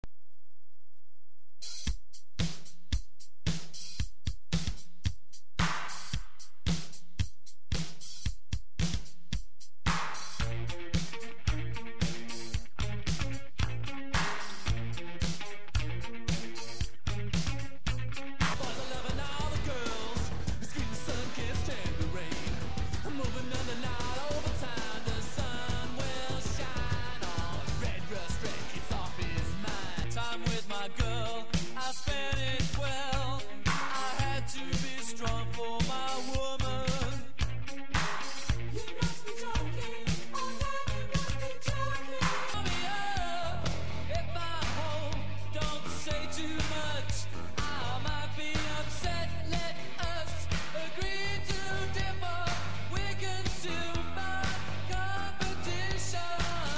テープ に ハサミ を入れて文字通り、切り貼り編集を施した
コラージュ音楽 もあったそうな。